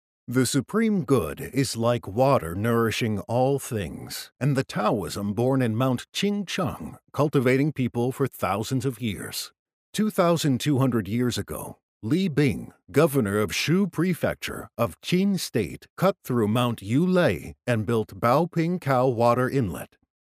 英语样音试听下载